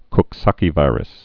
(kk-säkē-vīrəs, kŏk-săkē-)